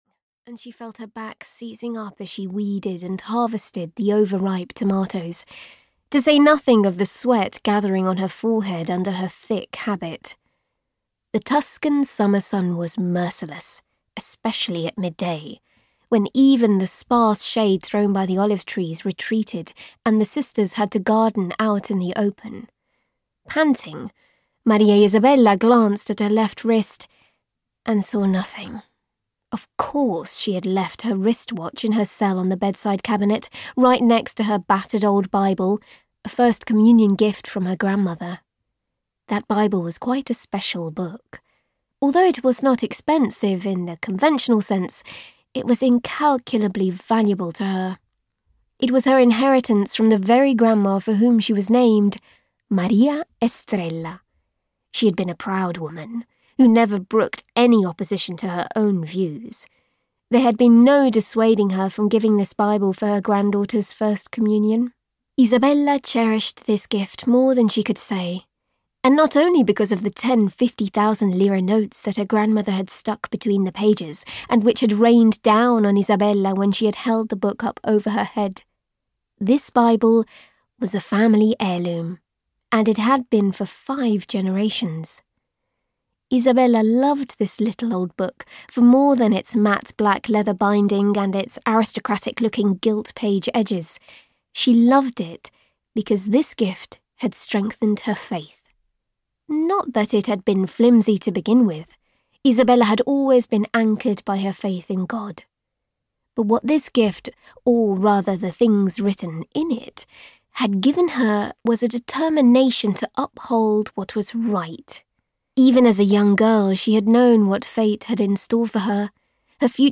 Death at Noon (EN) audiokniha
Ukázka z knihy